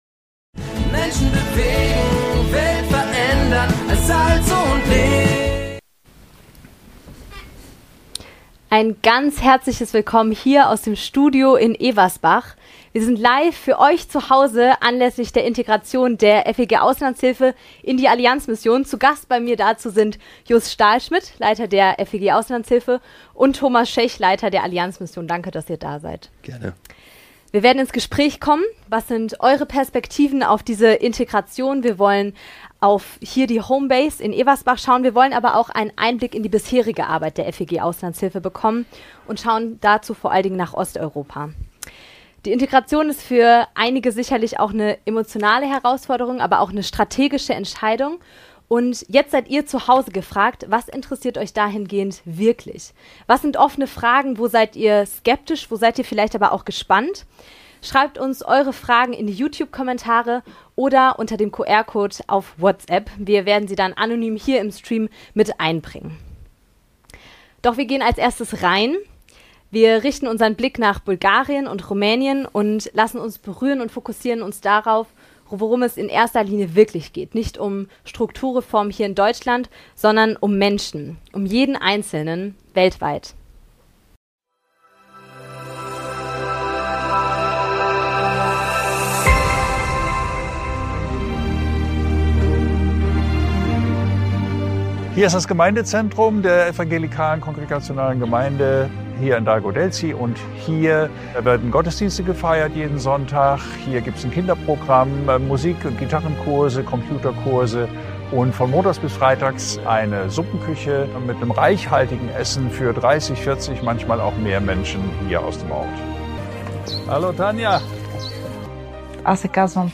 Besser helfen in Osteuropa - FeG Auslandshilfe wird Teil der Allianz-Mission | Live-Talk ~ Weltbeweger - Der Podcast der Allianz-Mission Podcast